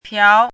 “朴”读音
piáo
piáo.mp3